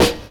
0210 DR.LOOP.wav